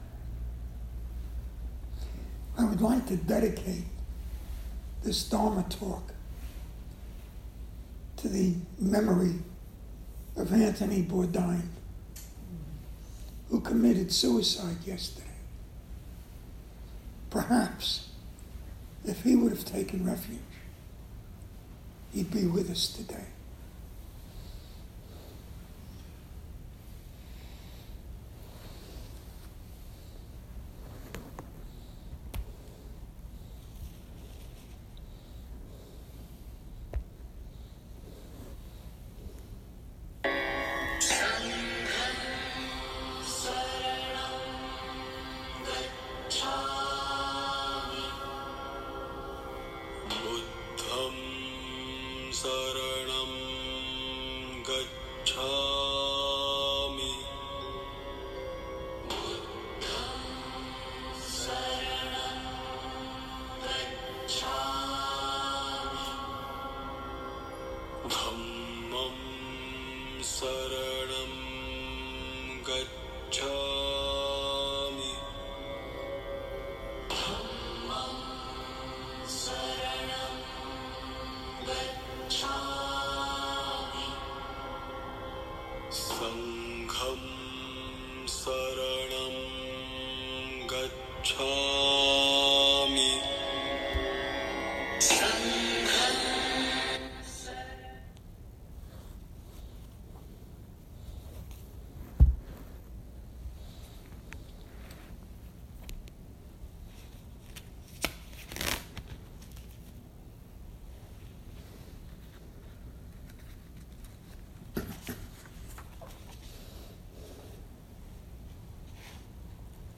Taking Refuge: Dharma Talk